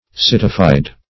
Citified \Cit"i*fied\, a. [City +-fy.]